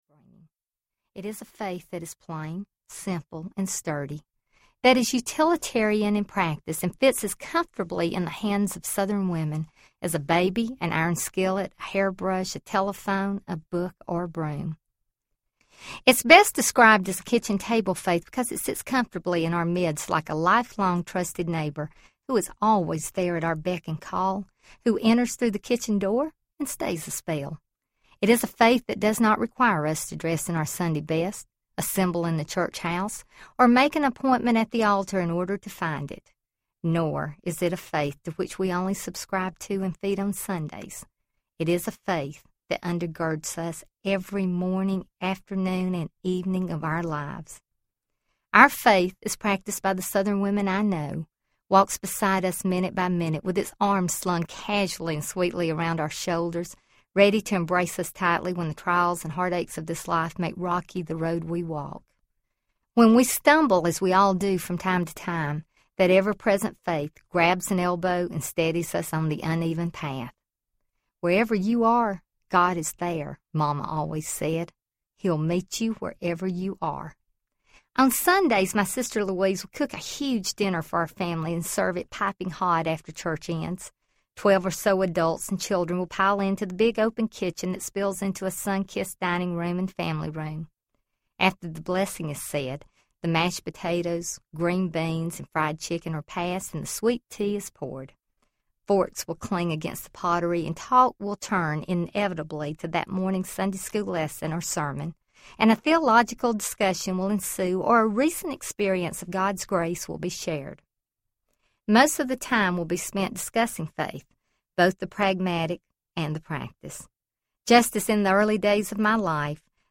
What Southern Women Know About Faith Audiobook
5.85 Hrs. – Unabridged